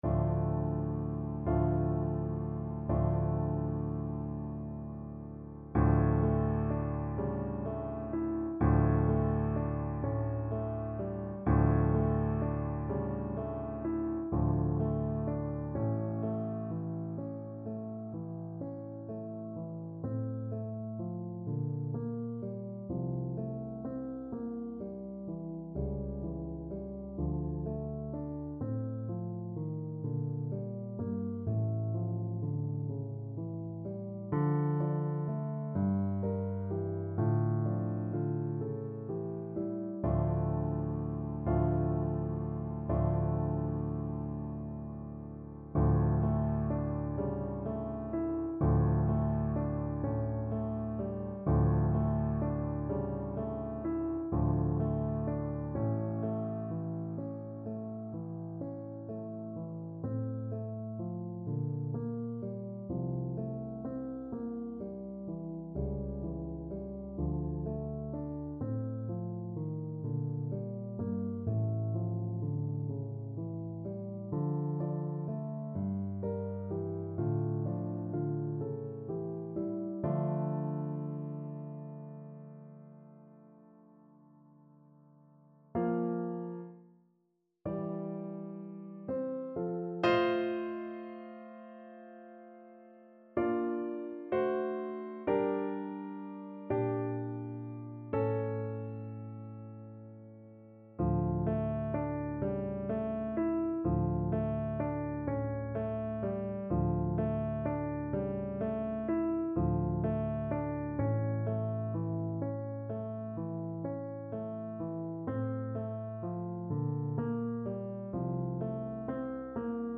Play (or use space bar on your keyboard) Pause Music Playalong - Piano Accompaniment Playalong Band Accompaniment not yet available reset tempo print settings full screen
D minor (Sounding Pitch) (View more D minor Music for Viola )
. = 42 Andante con moto (View more music marked Andante con moto)
6/8 (View more 6/8 Music)
Classical (View more Classical Viola Music)